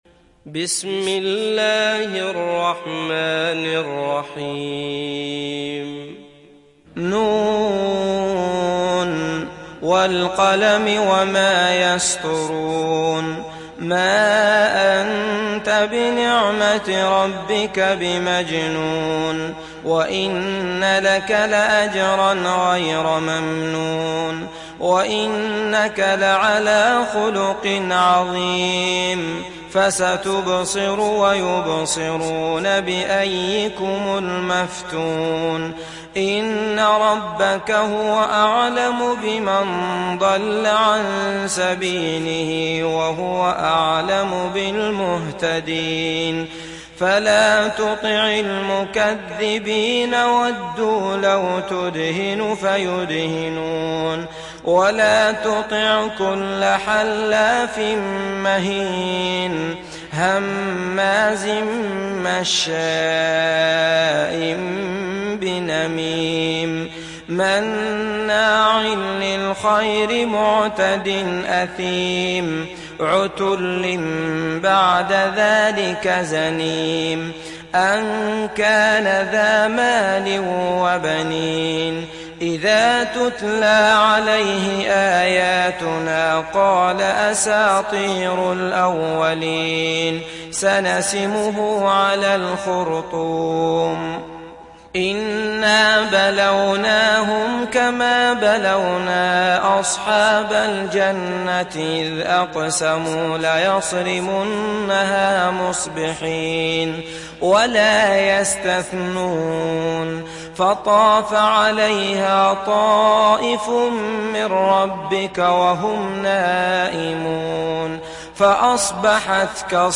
Kalem Suresi mp3 İndir Abdullah Al Matrood (Riwayat Hafs)